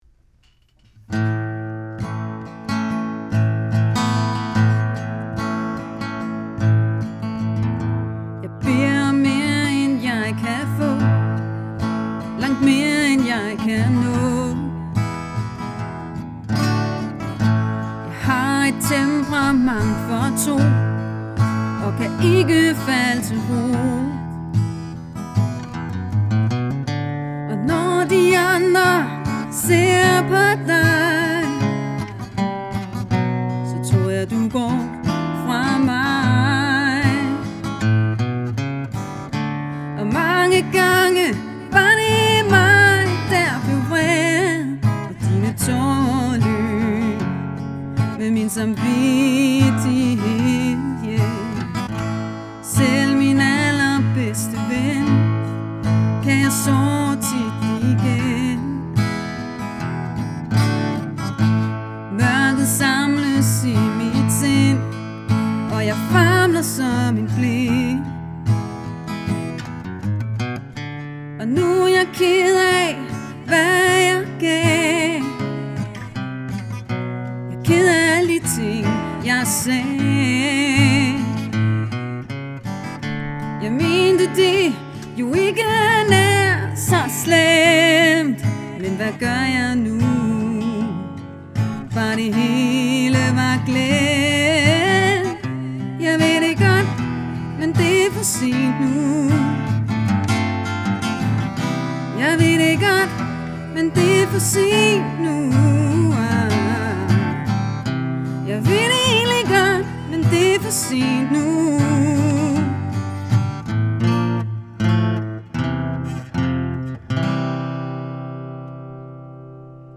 to garvede musikere med hver over 30 års erfaring på scenen